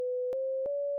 Still hear steps in engine sound, they're smaller but still very audible, specially in the XFG. Don't know if steps is the right word for it so i made examples, these are very exaggerated, LFS sound isn't this bad.
steps.mp3 - 10.4 KB - 407 views